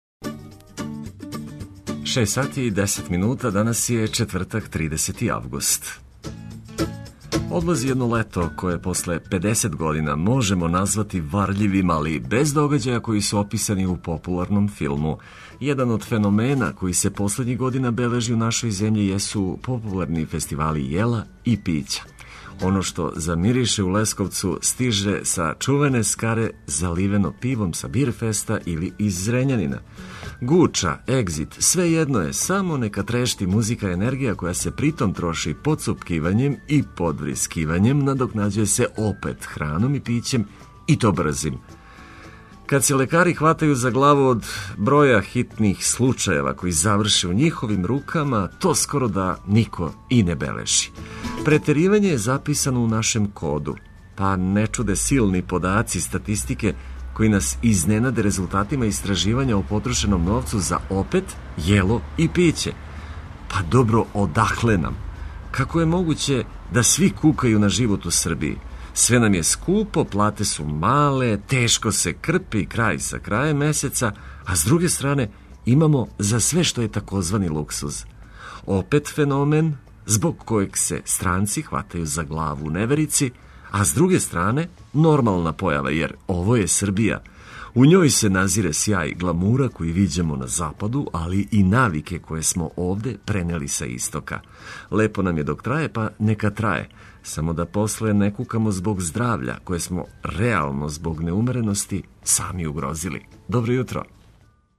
Уз важне приче и ведру музику oбјављујемо оно што вам може бити важно.
Разбудимо се уз сјајну музику, расположени и спремни за нове изазове.